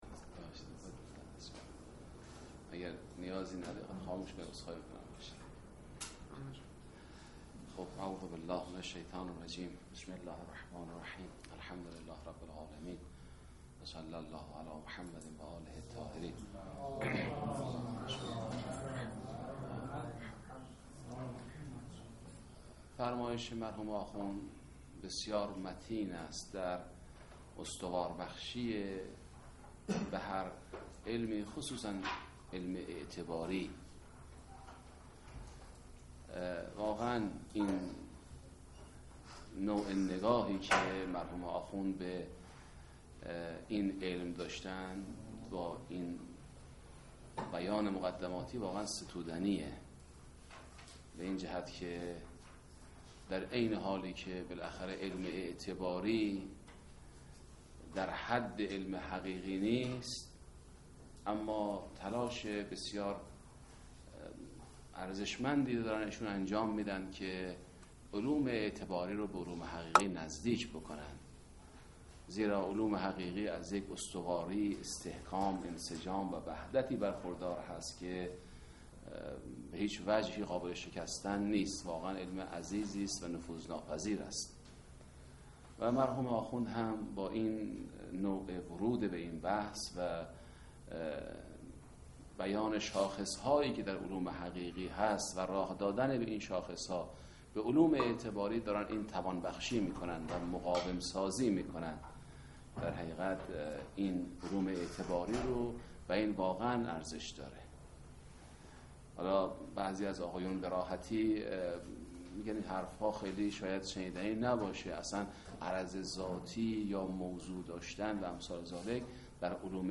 خارج اصول- جلسه13